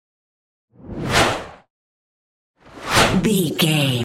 Whoosh fast x2
Sound Effects
Fast
futuristic
intense
whoosh